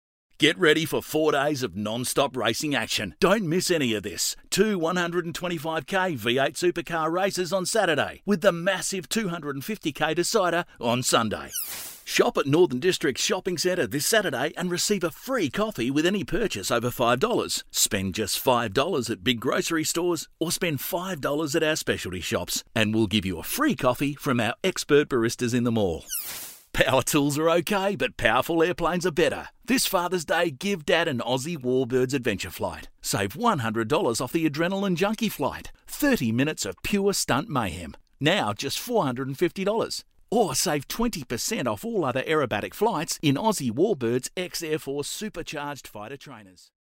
I guess you can call me ‘the everyday bloke’.
• Hard Sell